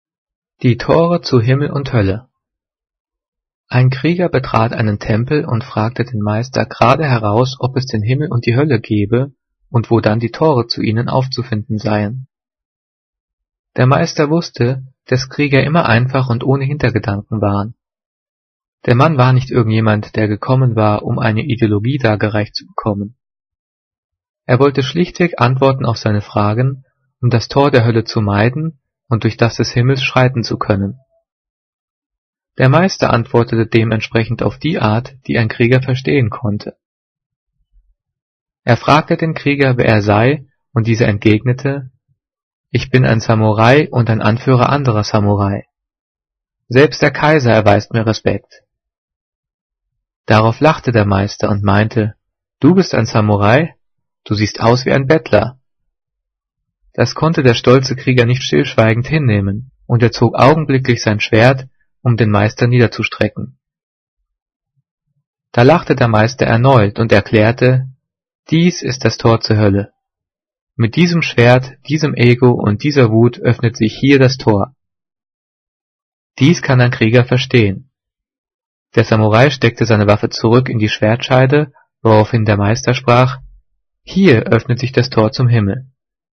Diktat: "Die Tore zu Himmel und Hölle" - 9./10. Klasse - Getrennt- und Zus.
Gelesen: